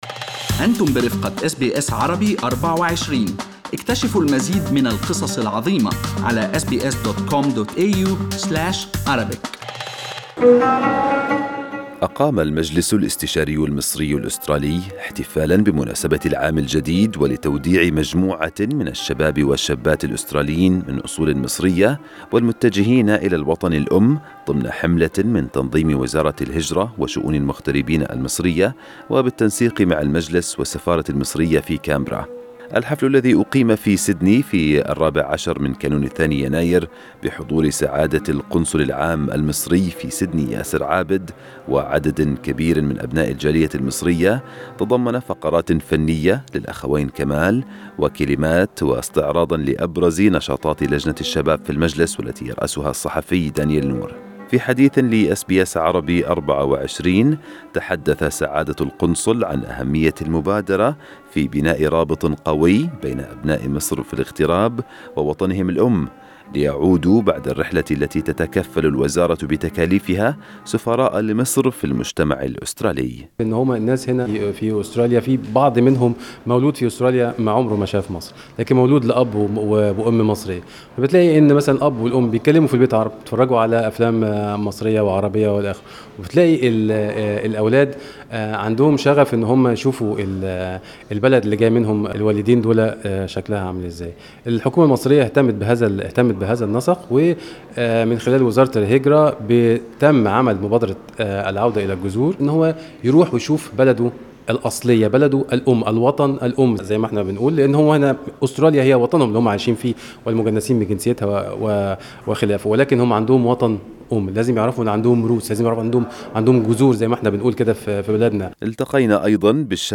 Source: SBS Arabic24 Source: SBS Arabic24 Source: SBS Arabic24 استمعوا إلى التقرير الصوتي المرفق بالصورة أعلاه.